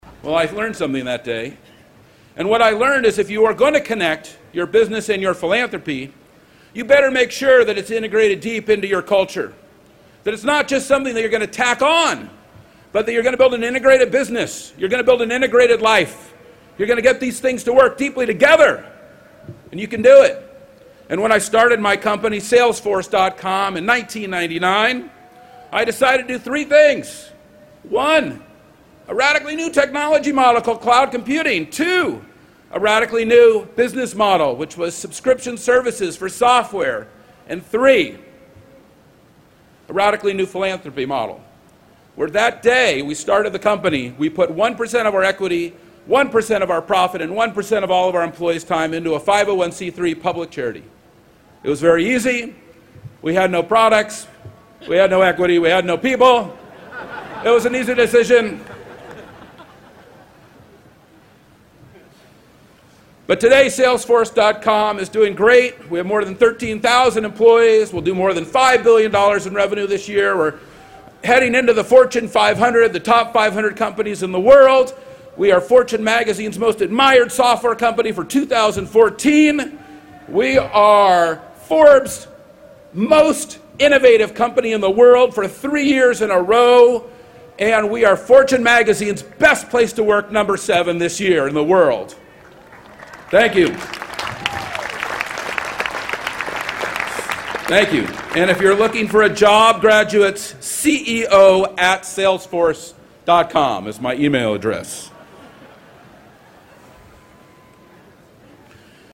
公众人物毕业演讲 第225期:马克·贝尼奥夫南加大2014毕业典礼演讲(11) 听力文件下载—在线英语听力室